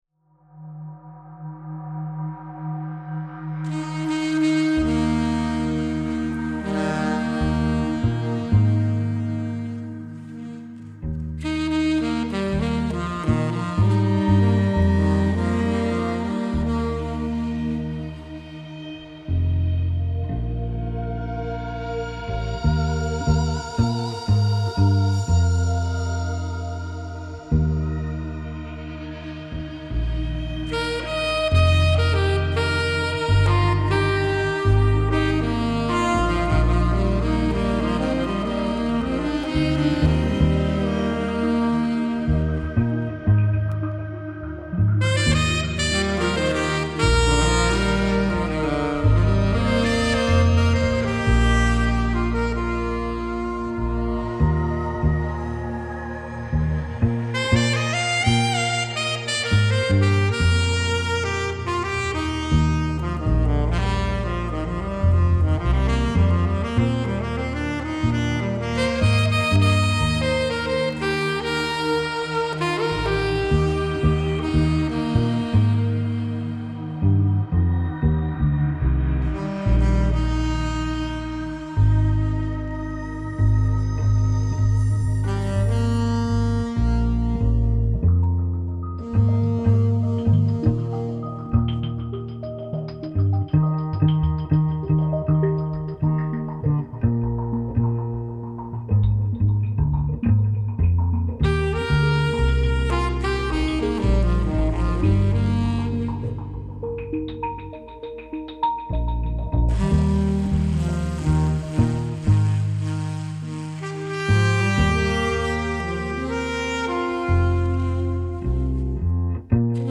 Ein klanglicher Vergleich meiner Alt-Saxofone Yanagisawa A-WO20 (links) und Selmer Ref54 (rechts)…